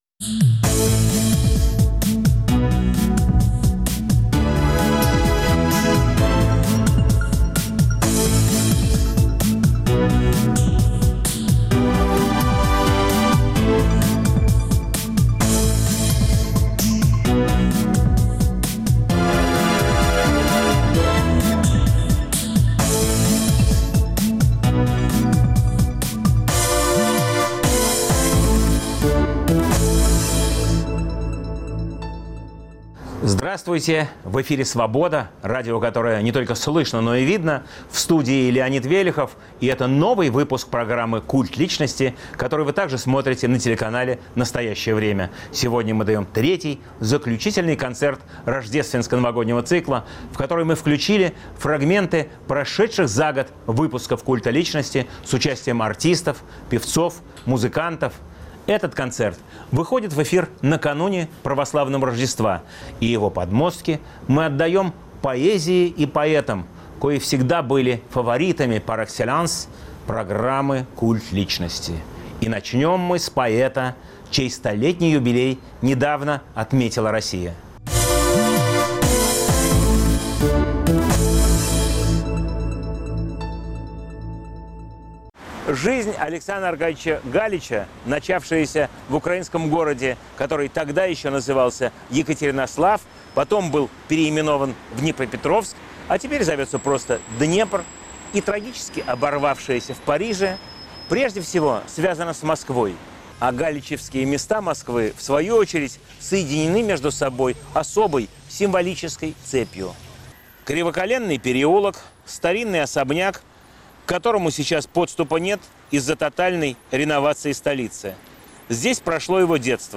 Культ личности. Поэтический концерт на Культе личности